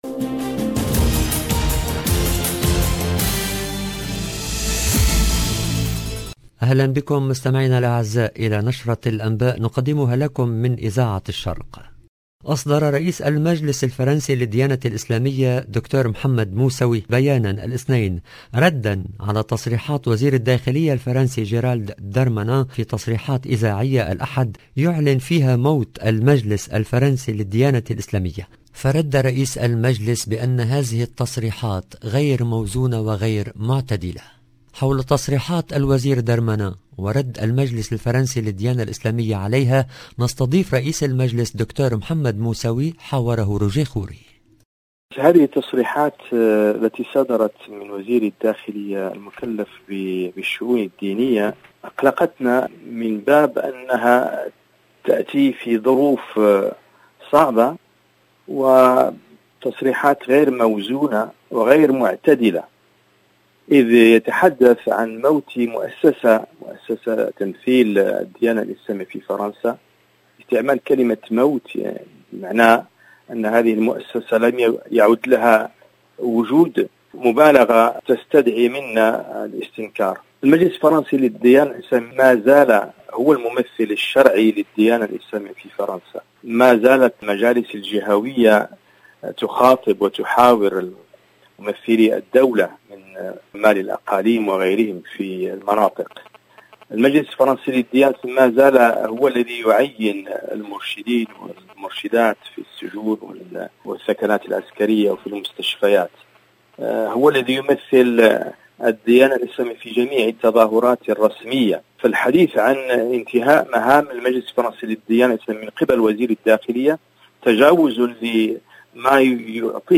LE JOURNAL DU SOIR EN LANGUE ARABE DU 13/12/21